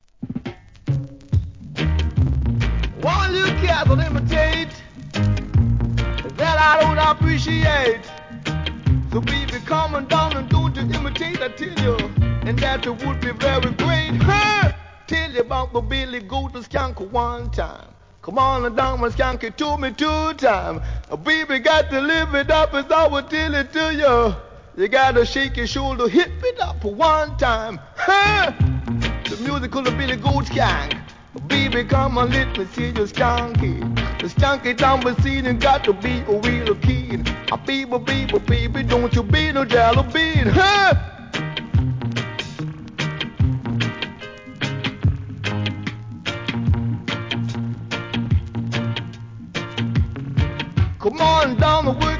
REGGAE
後半DUB接続!!